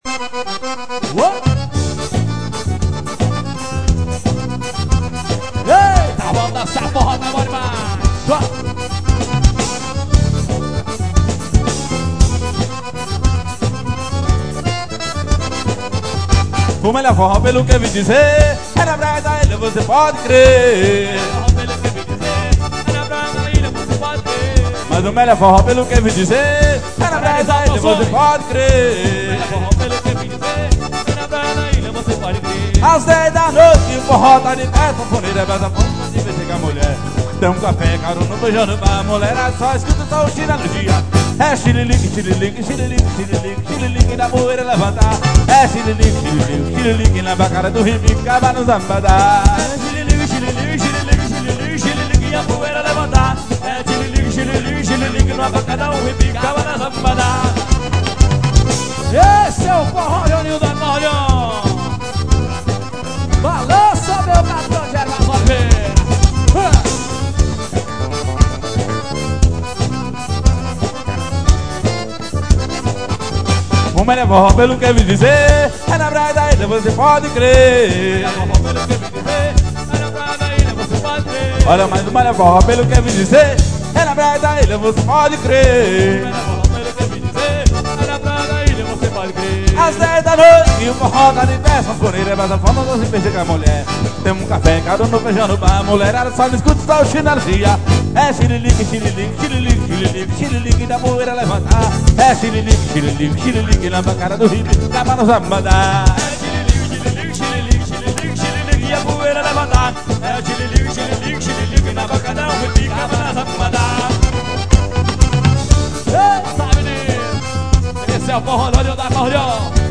pout-porri.